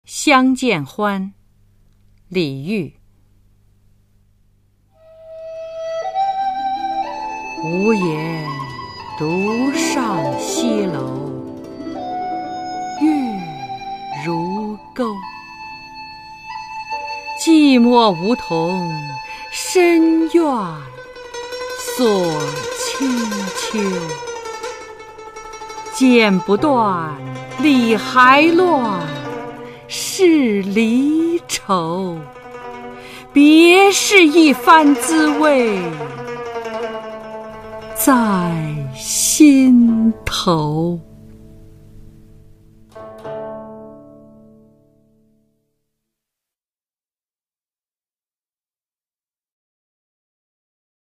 李煜-相见欢（无言独上西楼） 古诗朗诵
介绍： [五代诗词诵读]李煜-相见欢（无言独上西楼） 古诗朗诵